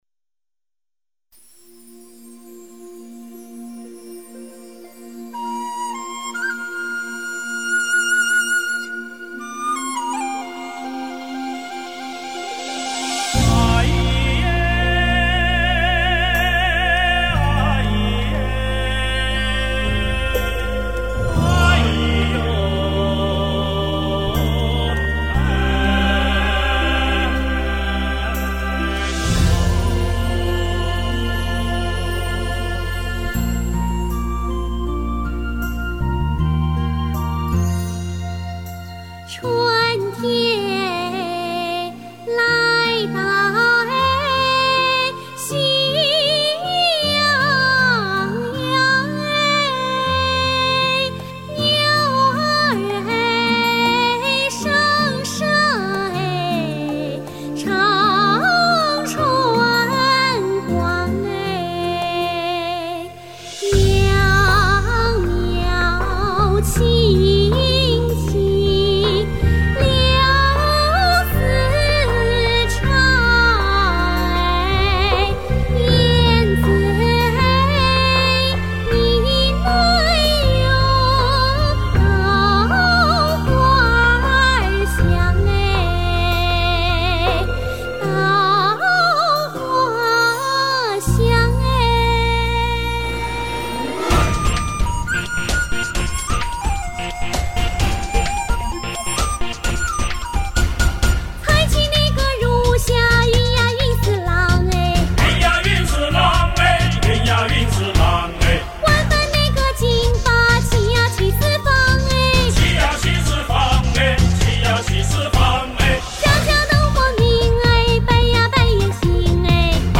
民歌